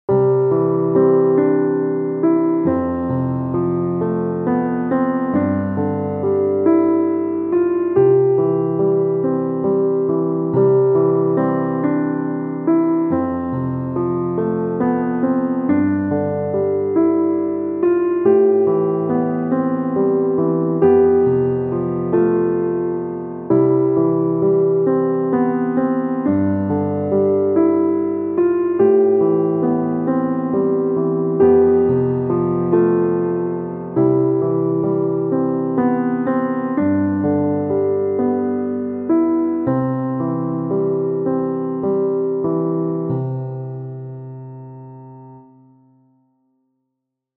作曲屋さんついにオフラインデビューを果たしたのでした✨🎵その時に即興で弾いた曲を仲間の人がSNSで流してくれたので覚えてしまい、アップしました♪